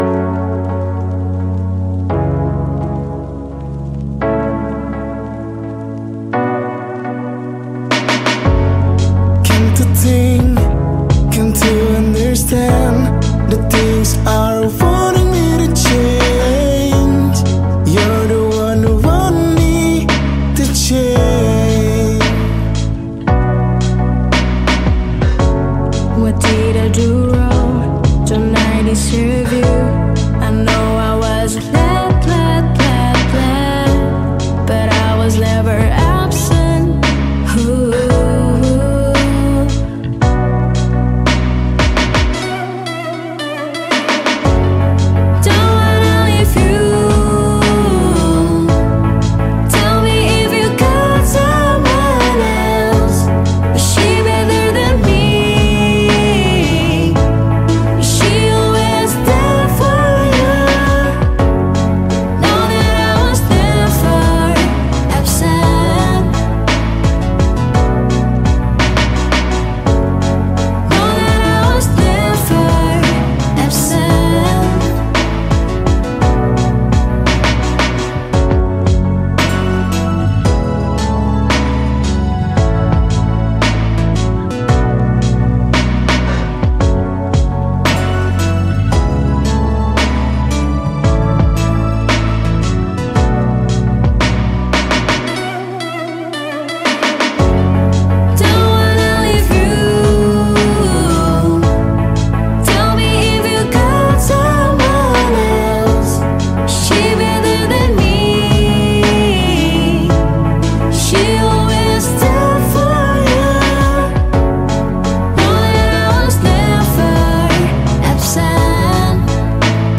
Electronic • Samarinda